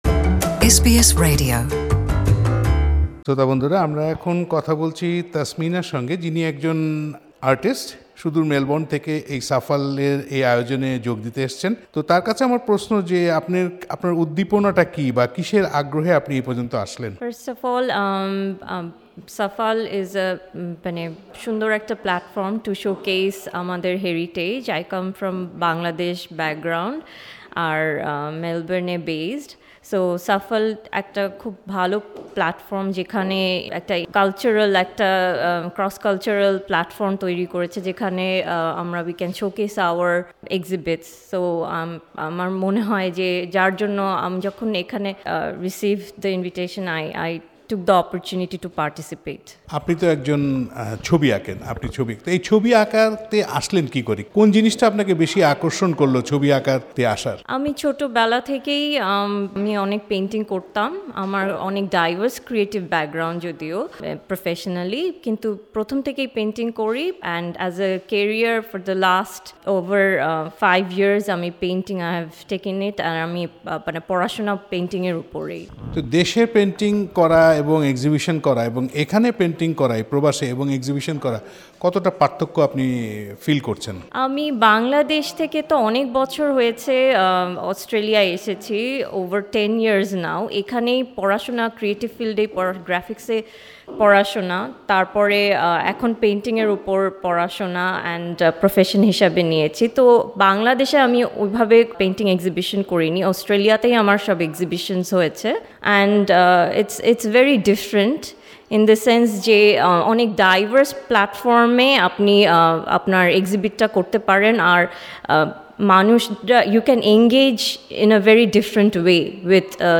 এসবিএস বাংলার সঙ্গে কথা বলেন তিনি।